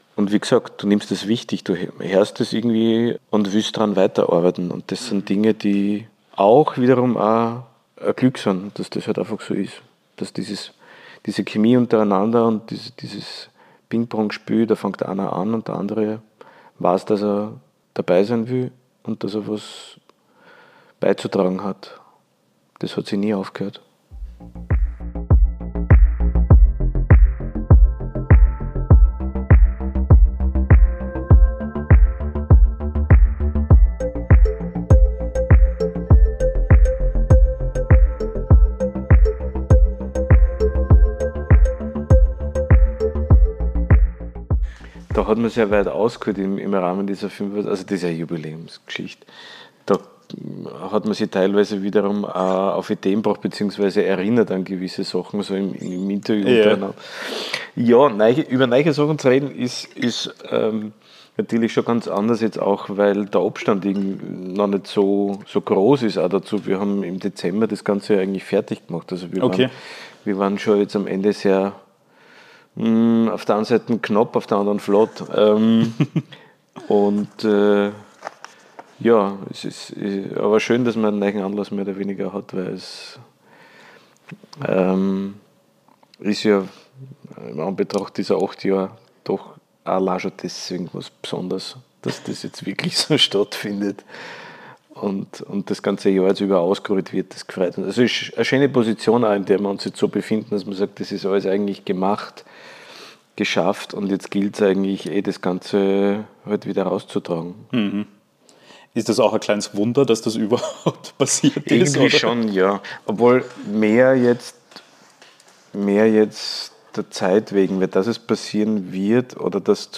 Ein Gespräch über musikalische Intuition – und warum am Ende wirklich alles ein Garten werden könnte.